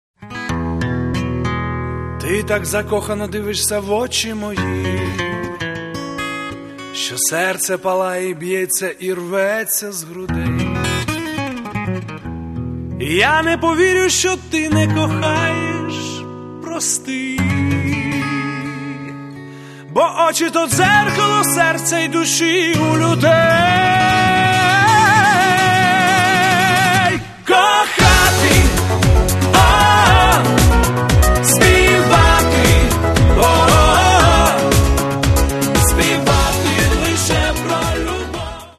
Каталог -> Поп (Легкая) -> Лирическая